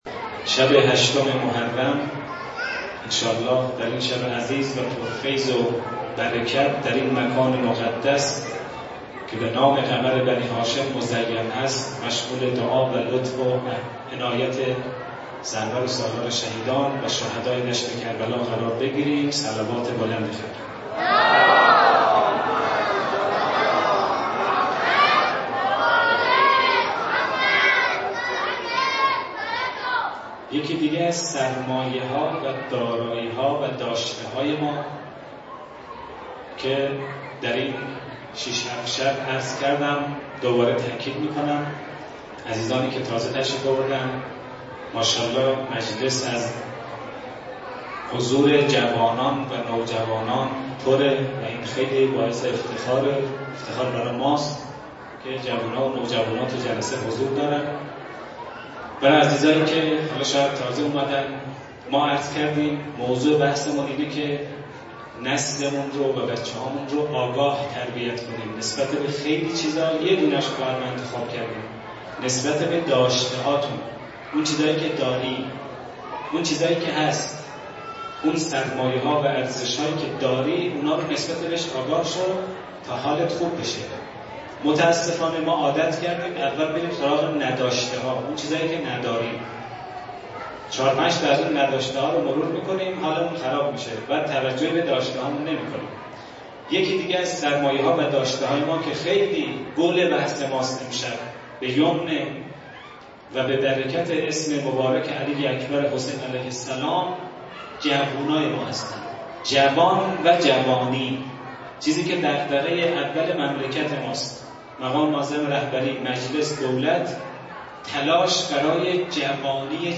مراسم ویژه شب هشتم ماه محرم در سقاخانه حضرت اباالفضل (ع) گلپایگان با حضور عاشقان حضرت اباعبدلله الحسین برگزار گردید.
سخنرانی و روضه خوانی